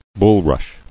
[bul·rush]